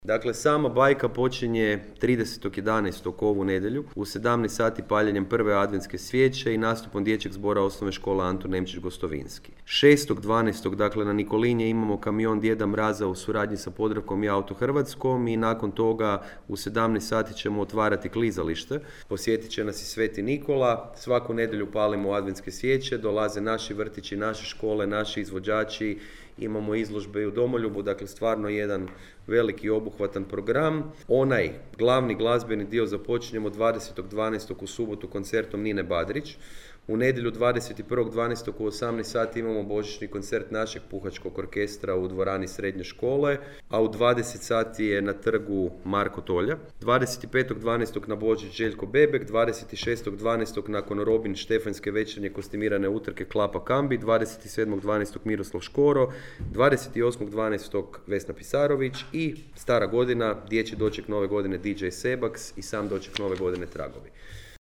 – rekao je gradonačelnik Jakšić na konferenciji.